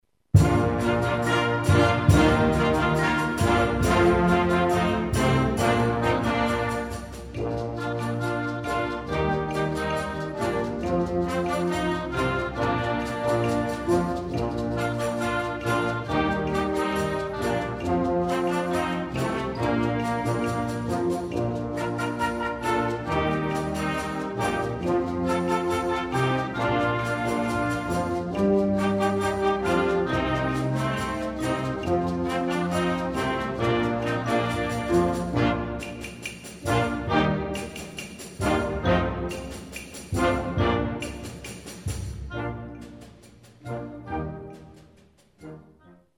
Genre: Band
Flute
Xylophone
Percussion 1 (snare drum, tambourine, bass drum)
Percussion 2 (maracas, claves)